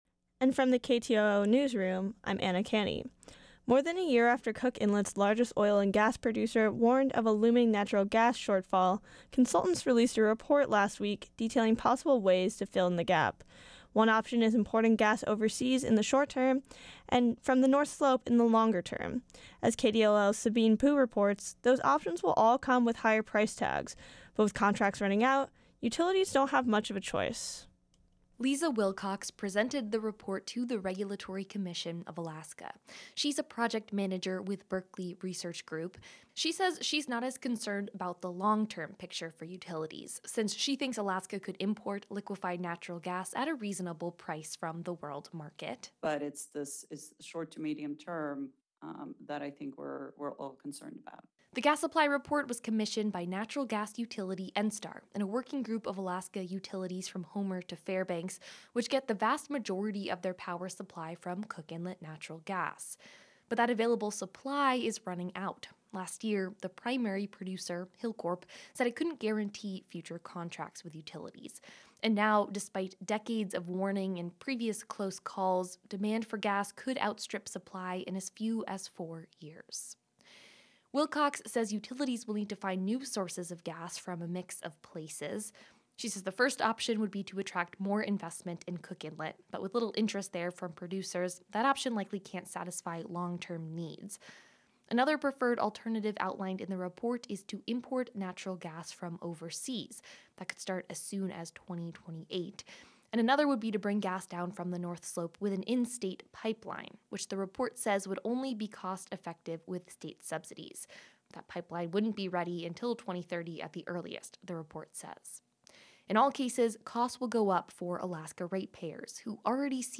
Newscast – Wednesday, July 5, 2023